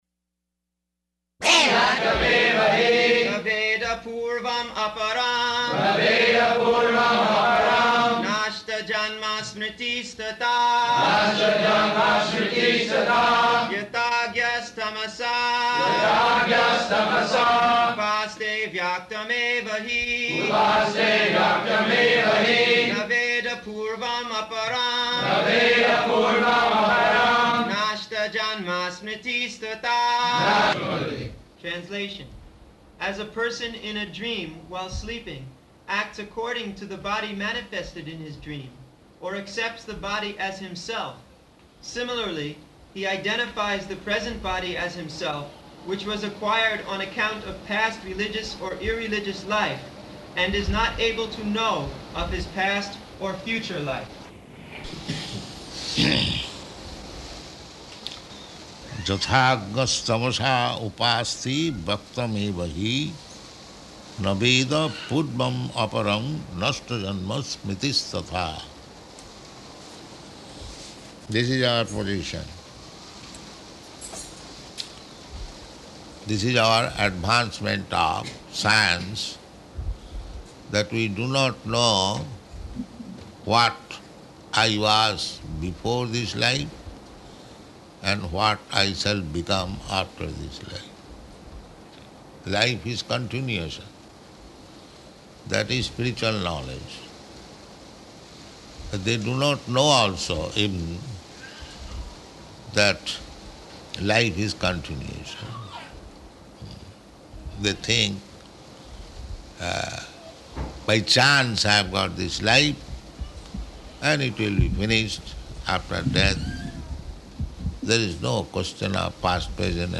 Type: Srimad-Bhagavatam
Location: New Orleans Farm